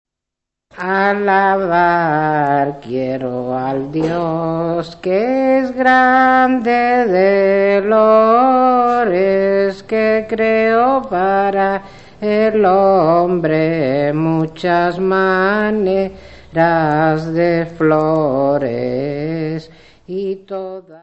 Cantares y romances tradicionales sefardies de Marruecos : el ciclo vital y el ciclo festivo : romances con referente épico histórico hispánico : romances sobre adúlteras y presos : romances sobre adúlteras = Traditional sephardic songs and ballads from Morocco
Notas:  Todas as gravações foram recolhidas em trabalho de campo realizado em Israel; Trablho de campo decorrido de 1979 a 1990; No do Serviço de Aquisições e Tratamento Técnico